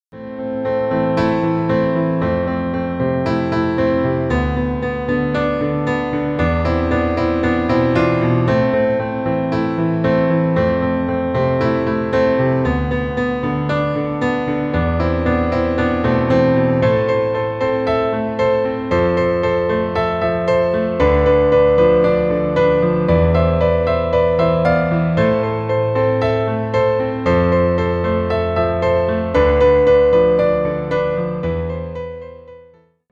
Поп Музыка
без слов